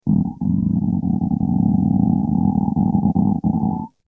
Stomach Growling
Stomach_growling.mp3